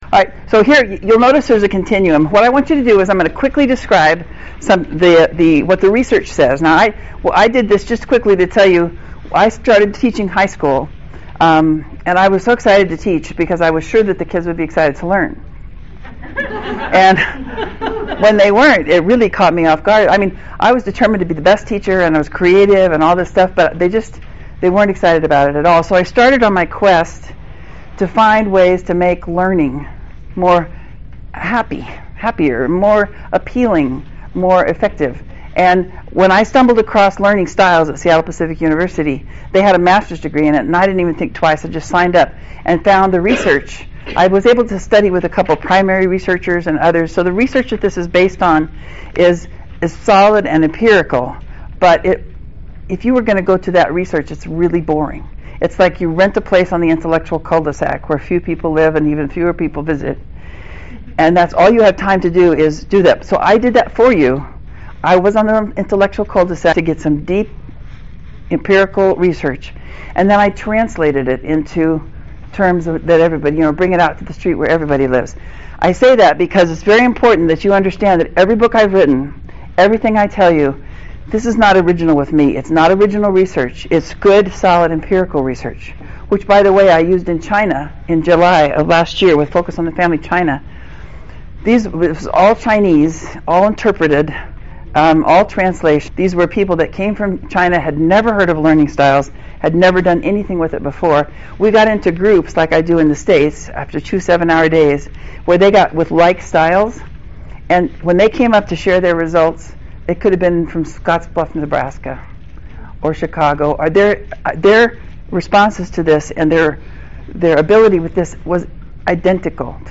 This dynamic presentation uses Learning Styles (inborn strengths and preferences “pre-wired” into each person) to give you quick and memorable ways to turn conflict into cooperation and bring out the best in everyone. You’ll walk away with immediately useful information and strategies for all kinds and all ages of learners.